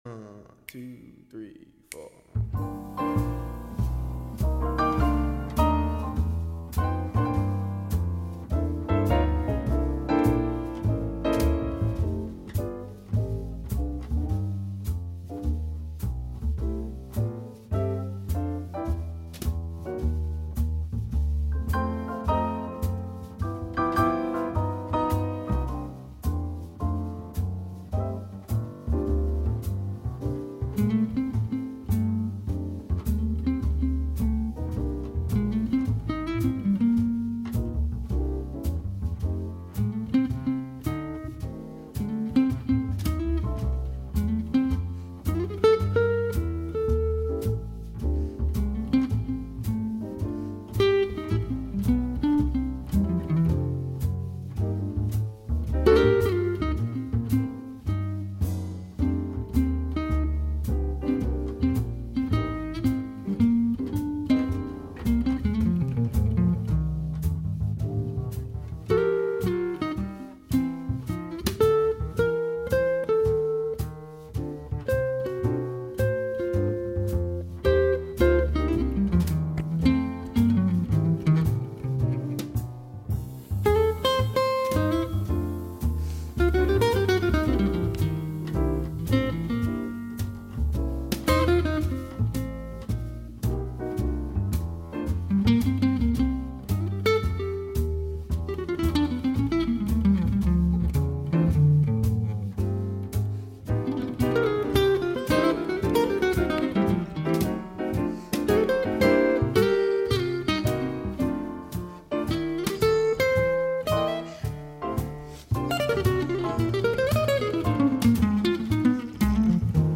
Finding Your Groove; 8/16/15; Set #1 Subtitle: cool jazz set Program Type: Unspecified Speakers: Version: 1 Version Description: Version Length: 1 a.m. Date Recorded: Aug. 16, 2015 1: 1 a.m. - 54MB download